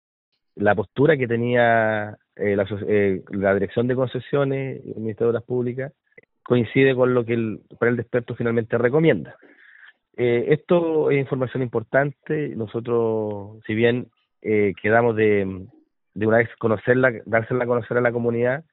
El delegado presidencial Jorge Alvial indicó que el monto propuesto se ajusta a lo que planteaba la dirección de concesiones.